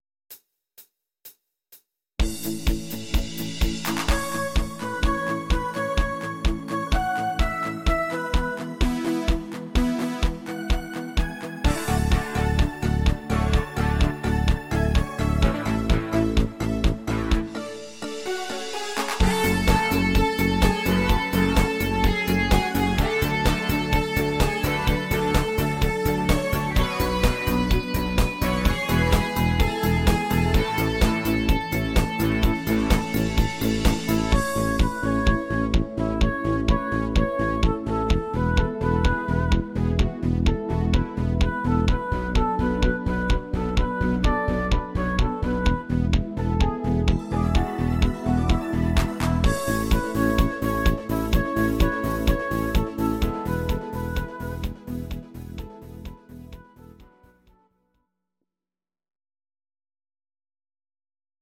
Audio Recordings based on Midi-files
Pop, German, 2010s